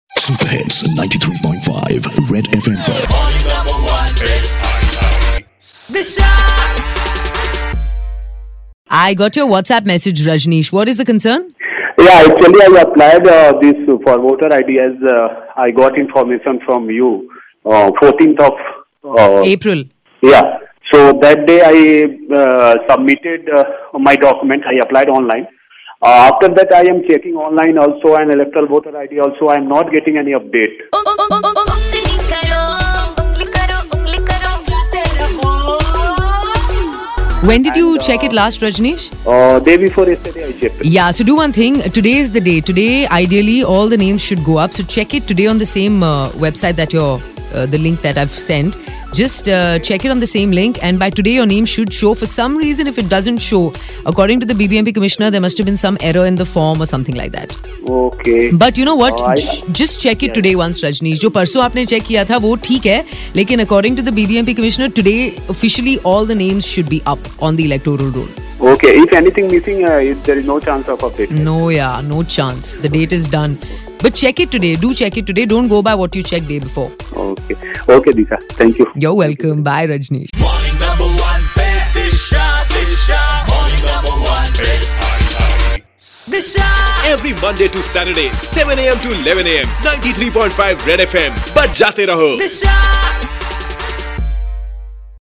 Caller tuned in to get details of Names reflecting on Electoral Roll